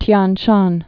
(tyän shän) or Tien Shan (tyĕn)